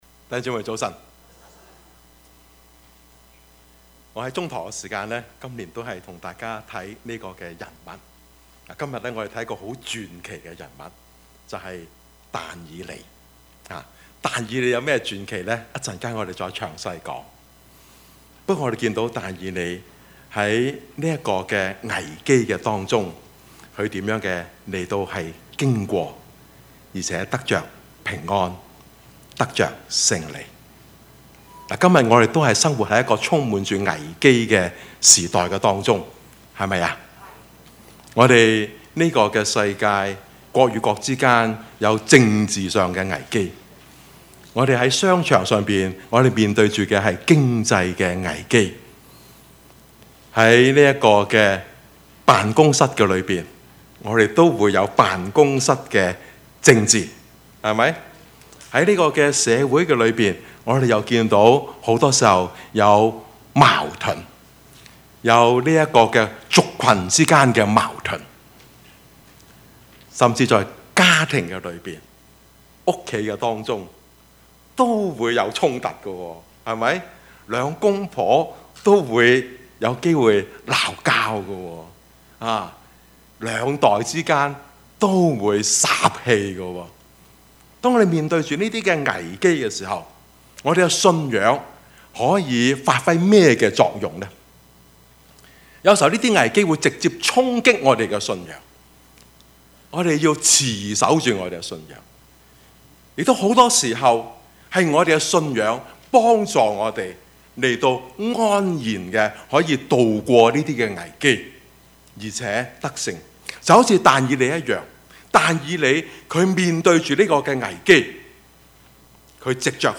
Passage: 但以理書 6:1-28 Service Type: 主日崇拜
Topics: 主日證道 « 矛盾人生 烈火同行 »